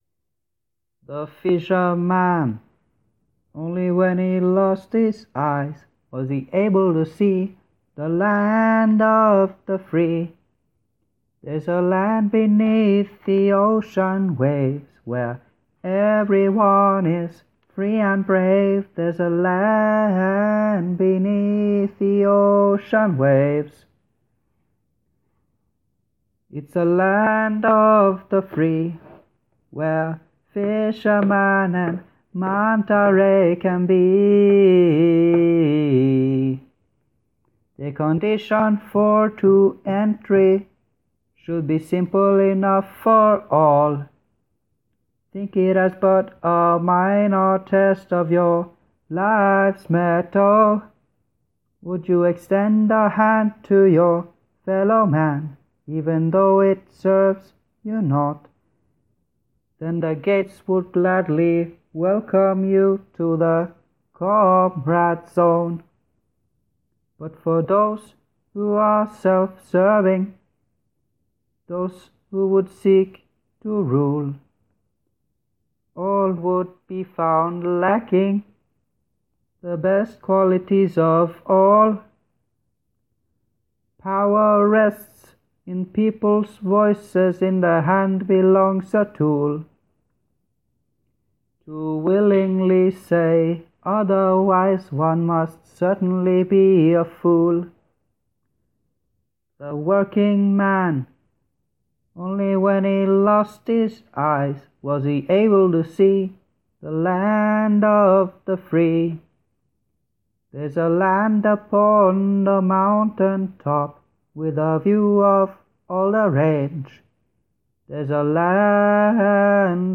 De fleste laga æ når æ sykla Nordkapp - Trondheim i 2019, me fugla og forbikjøranes som publikum.
Låt vedlagt